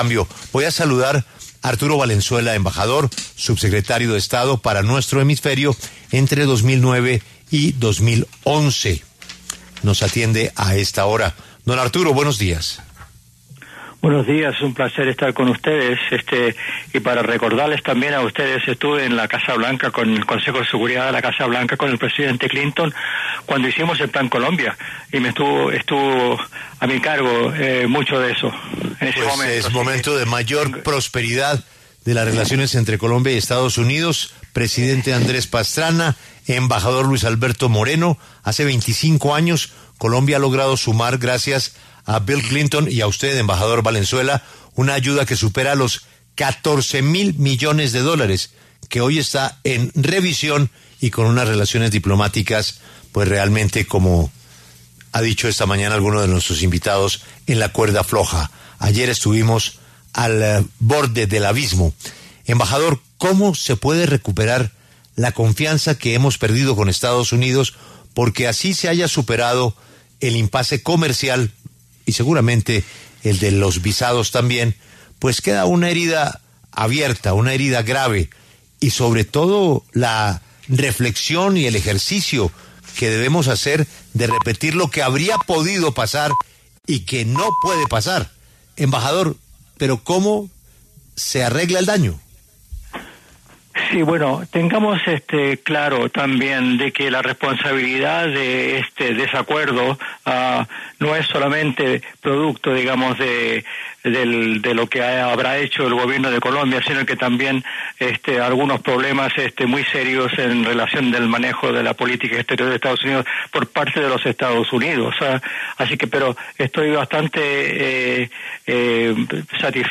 Arturo Valenzuela, ex subsecretario de Estado para el hemisferio occidental de Estados Unidos desde 2009 hasta 2011, pasó por los micrófonos de La W y habló sobre la tensión entre EE.UU. y Colombia.